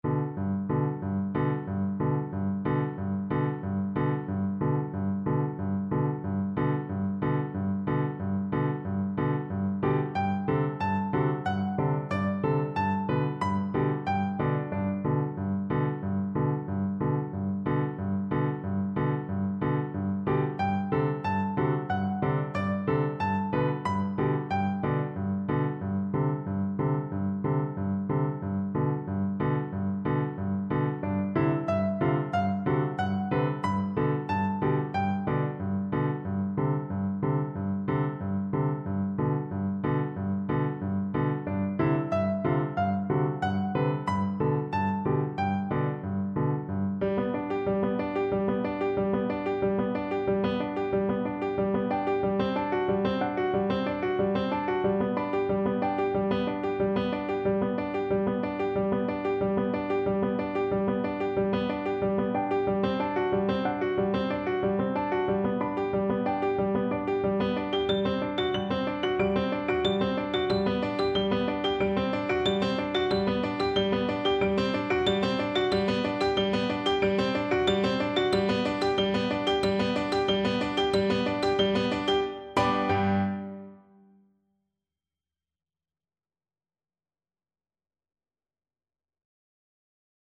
Classical Tchaikovsky, Pyotr Ilyich Chinese Dance from Nutcracker Suite, Op. 71a Viola version
Viola
4/4 (View more 4/4 Music)
G major (Sounding Pitch) (View more G major Music for Viola )
Allegro moderato (=126) (View more music marked Allegro)
Classical (View more Classical Viola Music)
chinese_dance_tchaikovsky_VLA_kar3.mp3